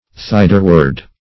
\Thid"er*ward\